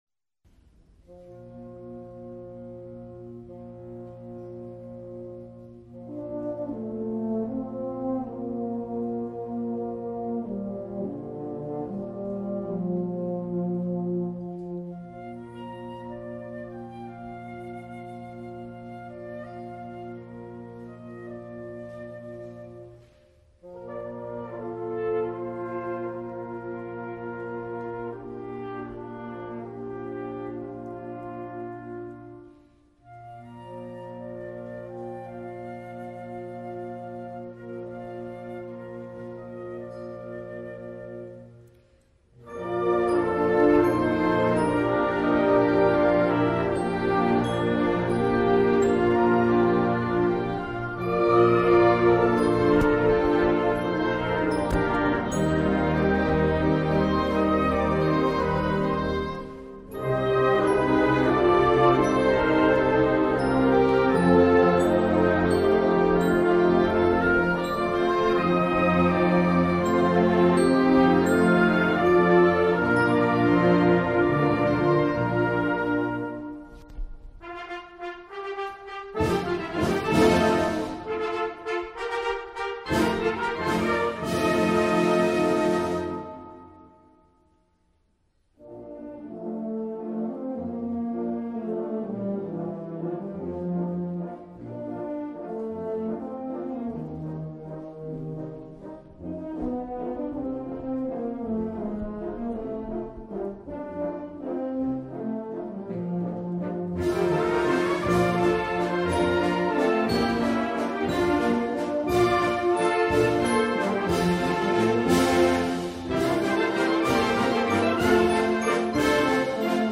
Konzertwalzer für Blasorchester
Besetzung: Blasorchester